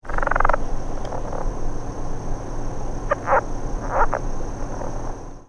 Males make chuckling calls during breeding to announce their territory.
Voice  (Listen)
call is a loud, short, low-pitched trill or rattle, lasting less than a second, given singly or in rapid sequences of 2 - 3 trills.
Males call at night with paired external vocal sacs.